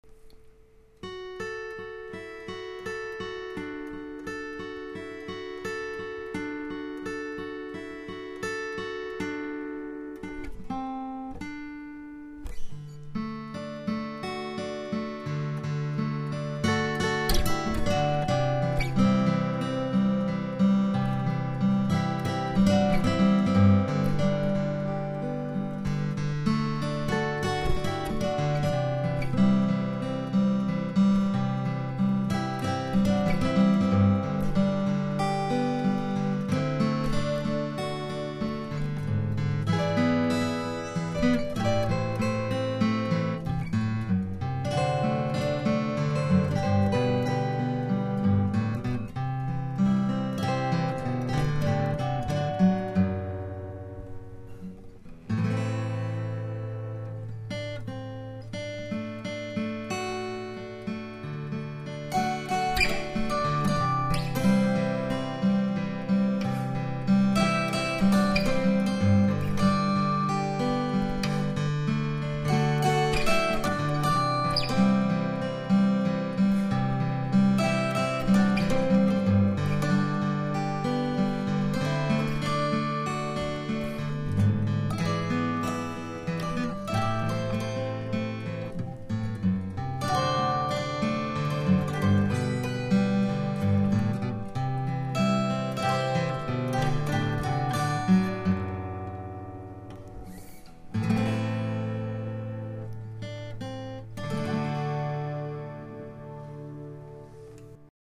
Played here with two acoustic guitars in standard tuning.